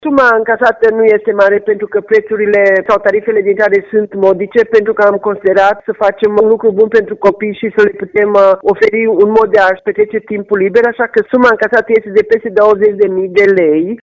Primarul municipiului Reghin, Maria Precup.